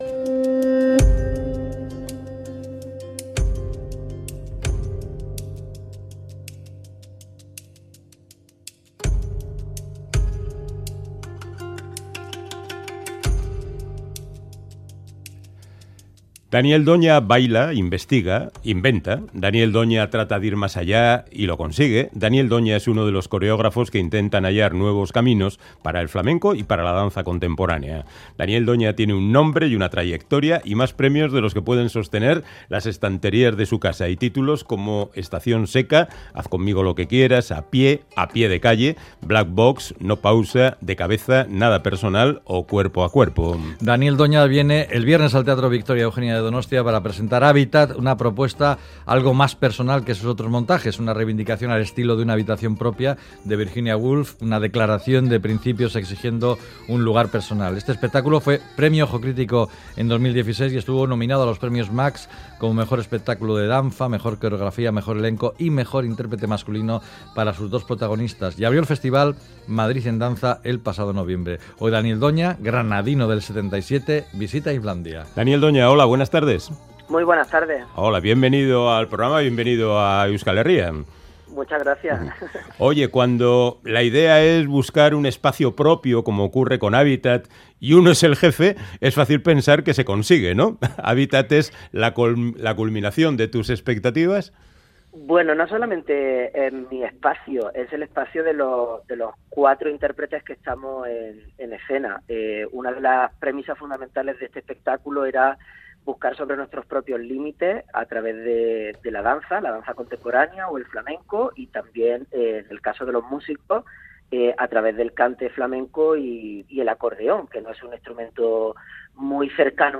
Charlamos